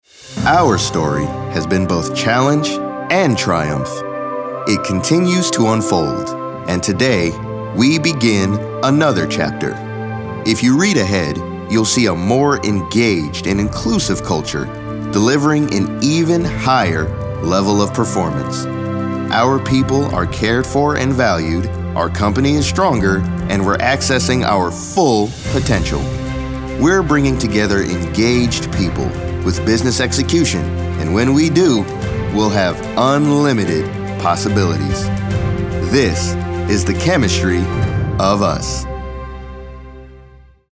caring, confessional, confident, friendly, genuine, informative, inspirational, mellow, serious, thoughtful, warm